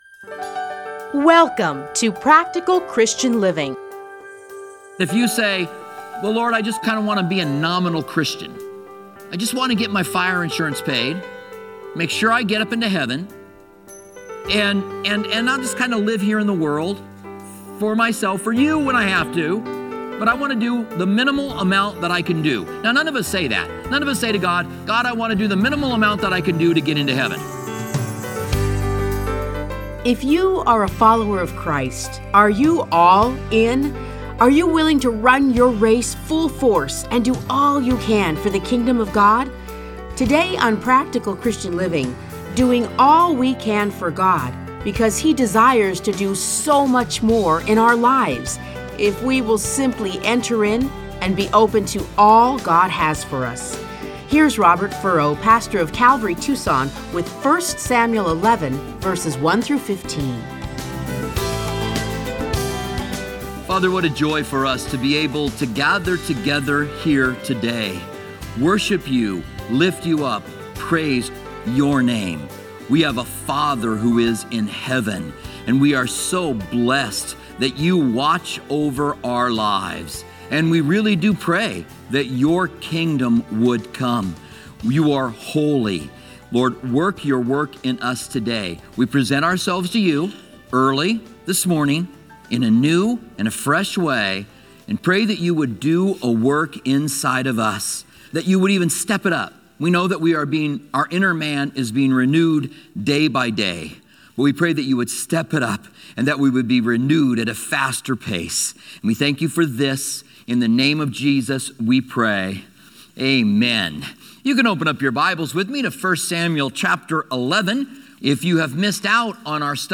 Listen to a teaching from 1 Samuel 11:1-15.